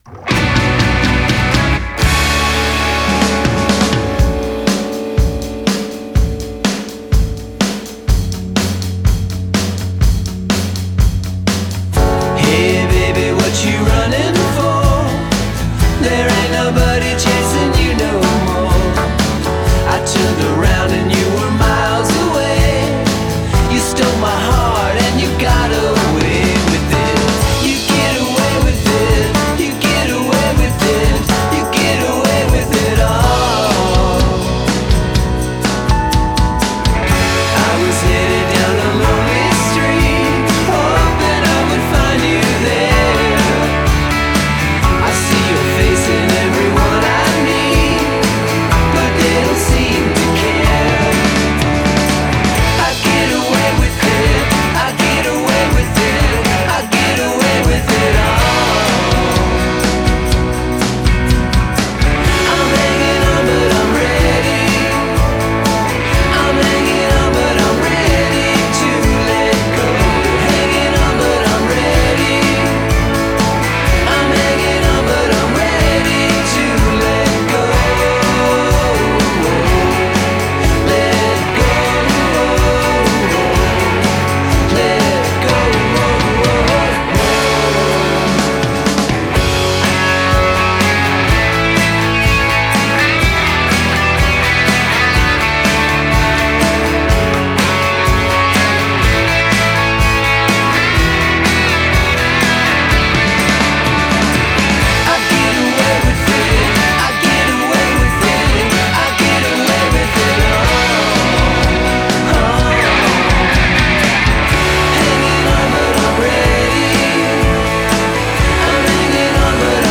breezy yet muscular poprock feel